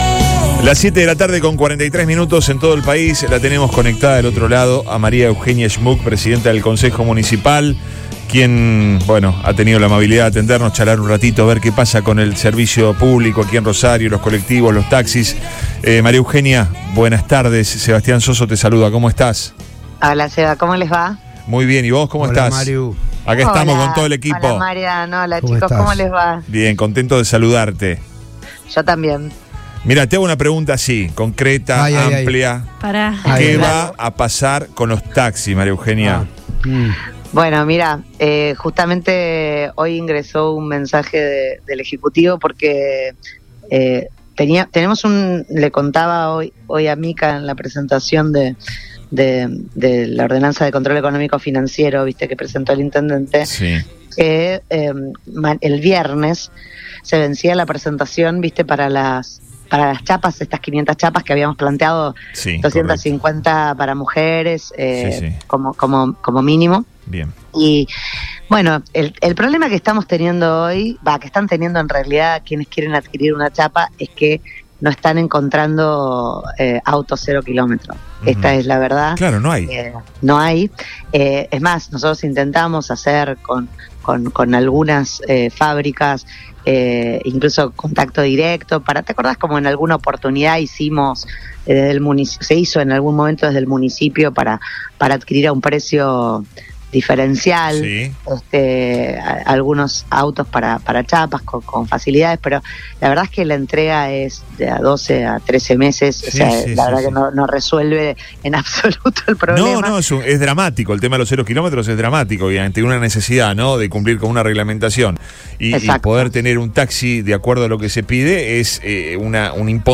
En medio de la faltante de habilitaciones de nuevas chapas de taxis y la problemática de las frecuencias de colectivos de la empresa “Cacique”, la presidenta del Concejo deliberante de la ciudad de Rosario, Maria Eugenia Schmuck, habló en Radio Boing sobre el tratamiento del servicio de transporte publico de la ciudad en el concejo y la búsqueda de respuestas en el corto plazo.